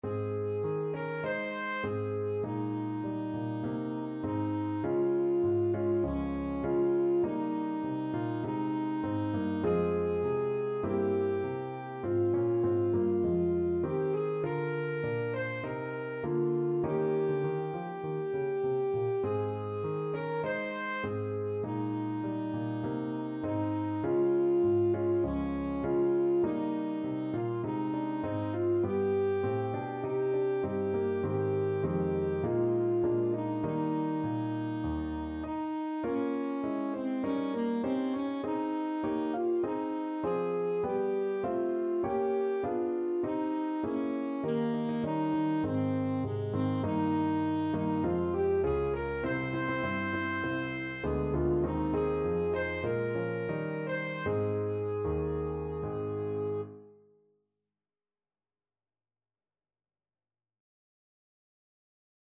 Alto Saxophone
4/4 (View more 4/4 Music)